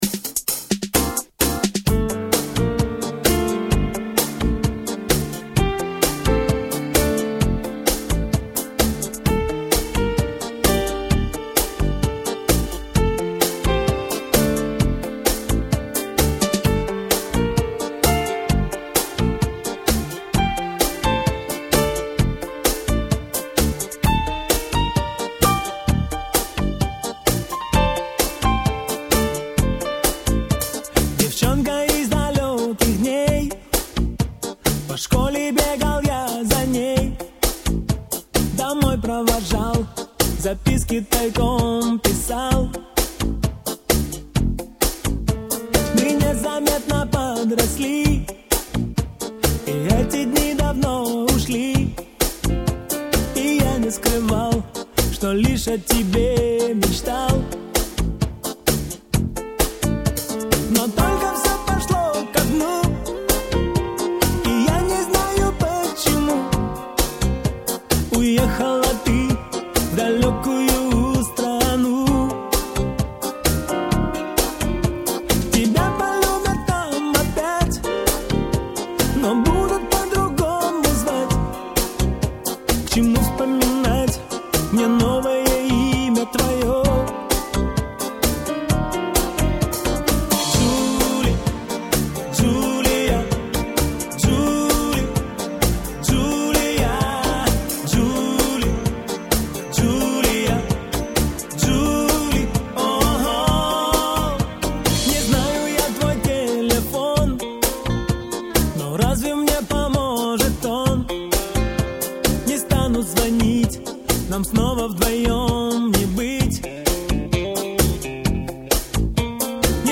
Популярная музыка